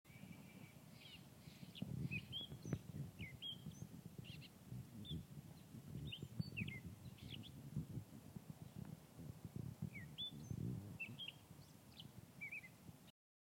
Gray Catbird
This is one of two singing male Gray Catbirds along the Old Guadalupe Trail on San Bruno Mountain. This species is a rare vagrant in California and I believe this is only the second time that more than one Gray Catbird has been seen at a single location in the State.
GrayCatbird1.mp3